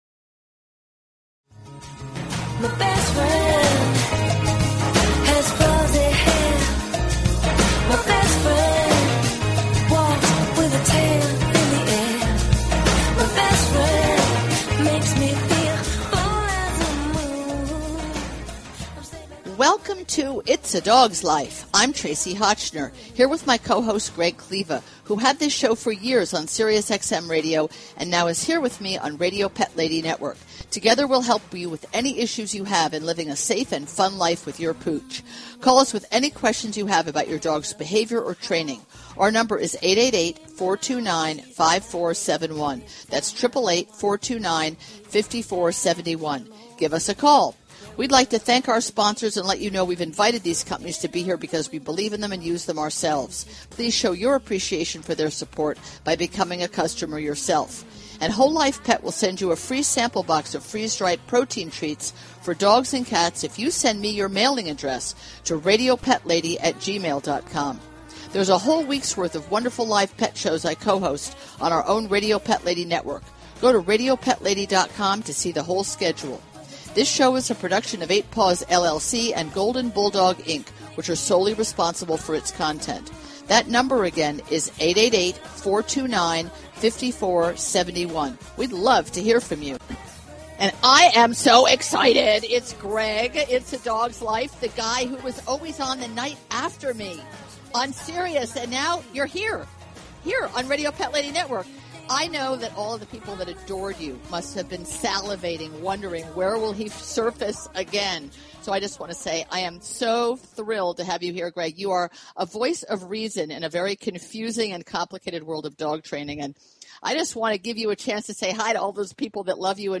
Talk Show Episode, Audio Podcast, Its_A_Dogs_Life and Courtesy of BBS Radio on , show guests , about , categorized as